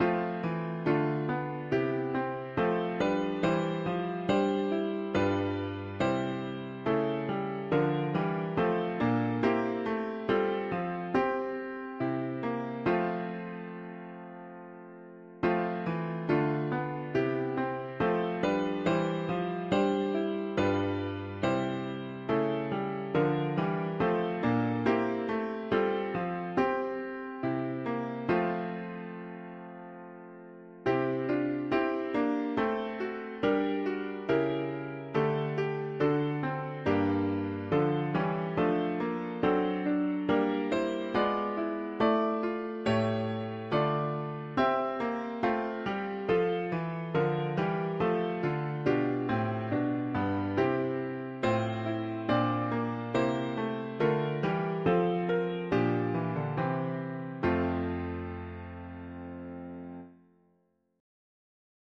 Key: F major
Tags english christian 4part morning